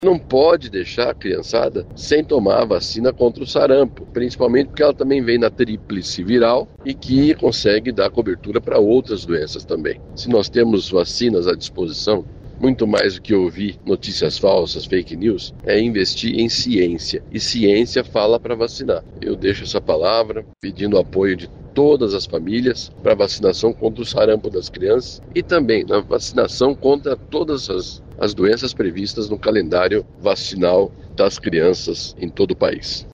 O responsável pela pasta destacou a necessidade da imunização.